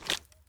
Weapon_Foley 06.wav